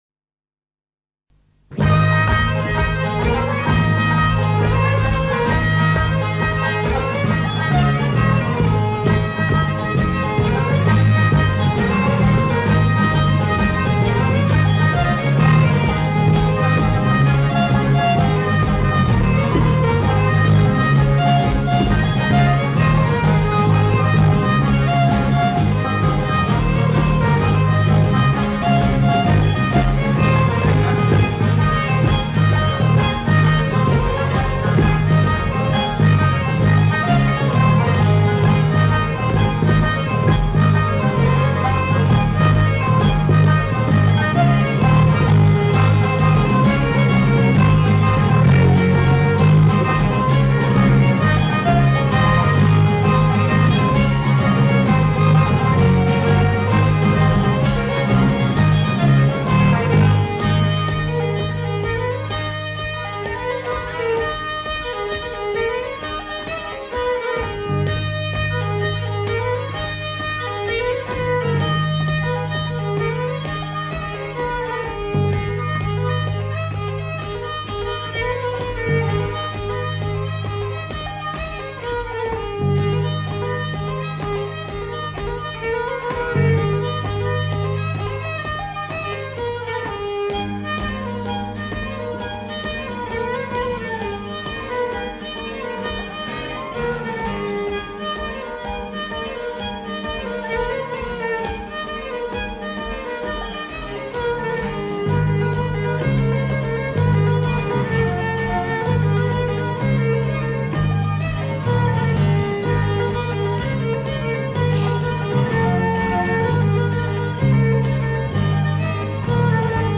Then there are a couple of sets recorded during a concert at the Little Theatre in Birkenhead.